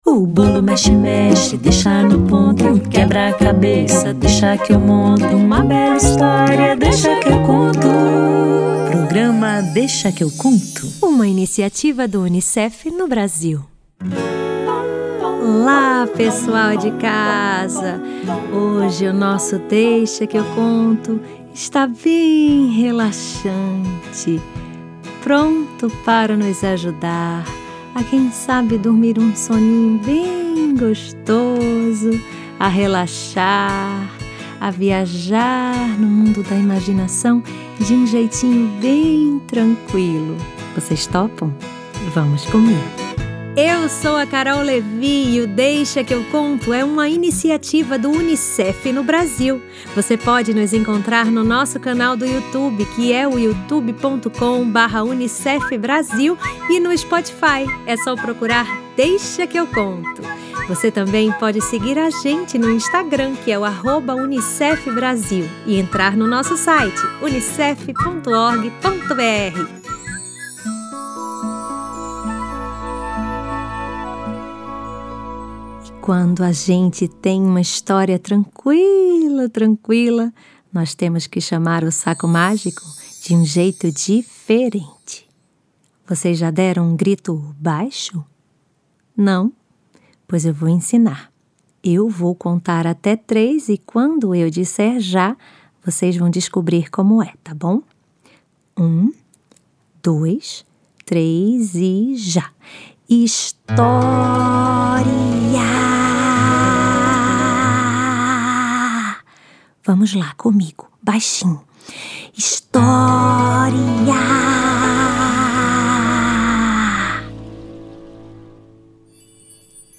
Hoje o programa é recheado de soninho e relaxamento. Vamos ouvir canções de ninar, a história da borboleta Antonieta, que estava morrendo de sono mas não queria dormir de jeito nenhum, e ainda vamos ouvir poesias.